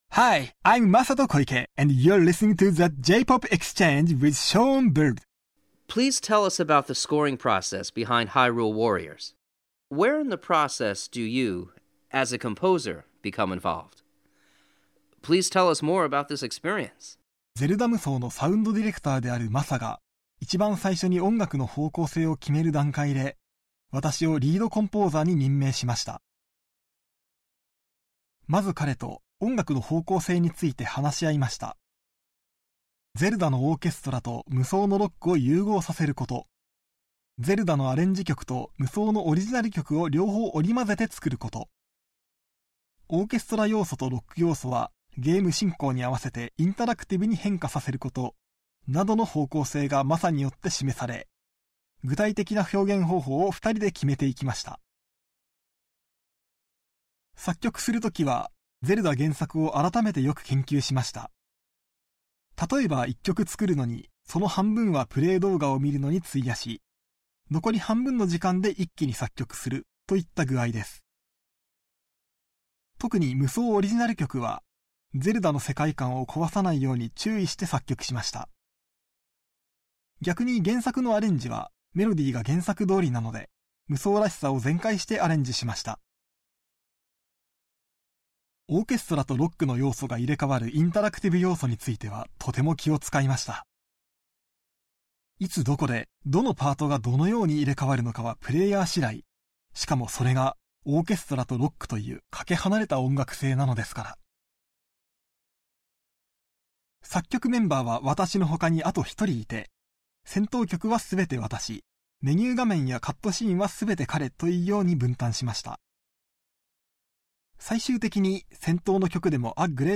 Exclusive Radio Interview